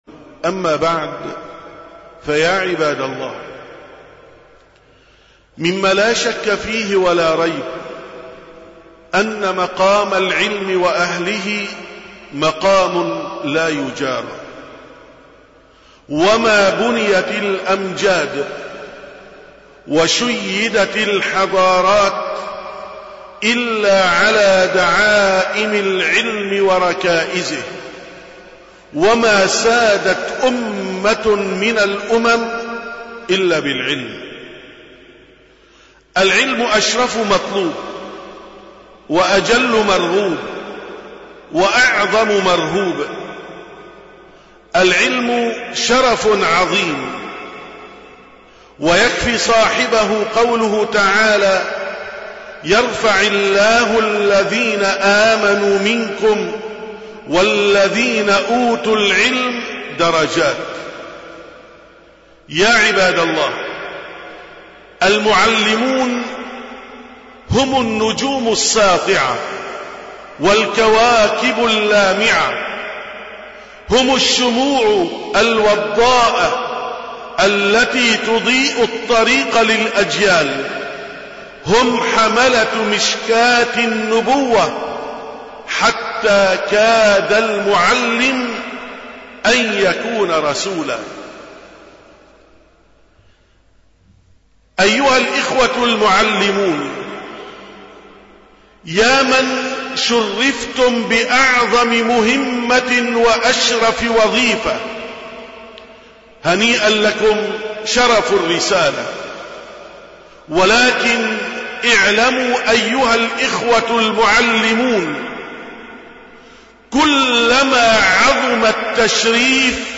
639ـ خطبة الجمعة: أيها الإخوة المعلمون